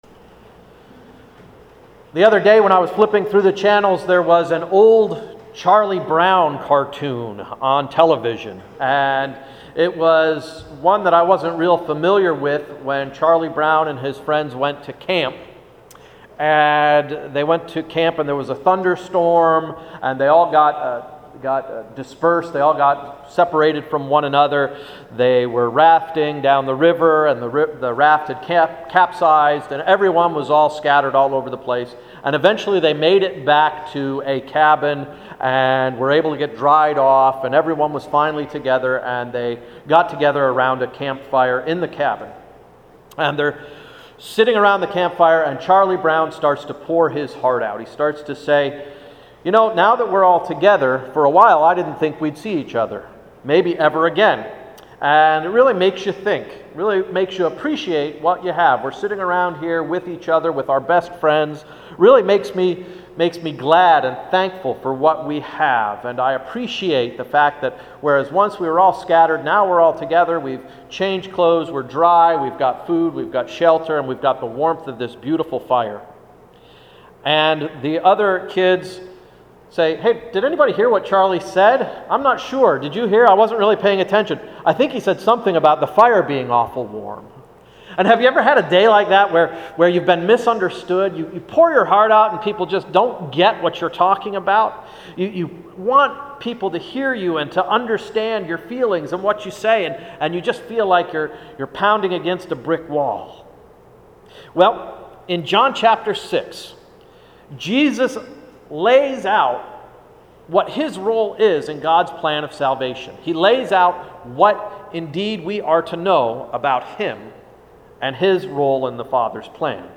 Sermon of August 12, 2012–“A Well-balanced Diet”